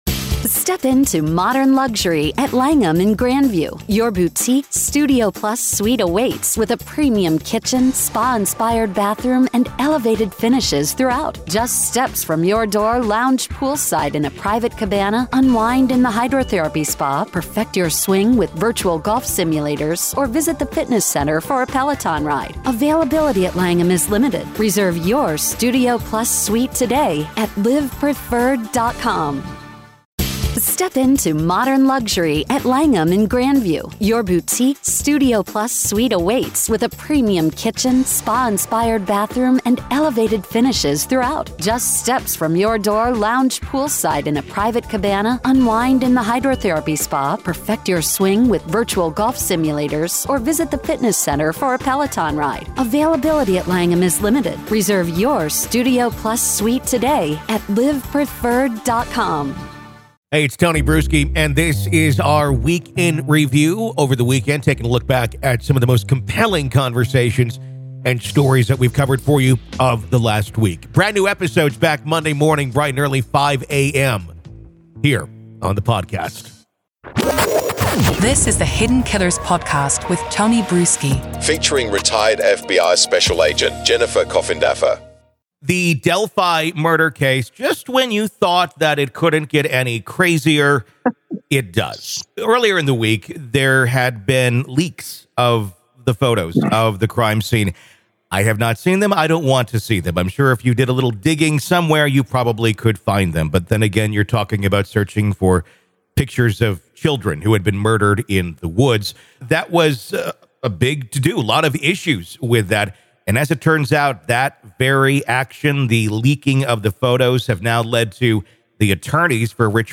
Welcome to the "Week In Review," where we delve into the true stories behind this week's headlines.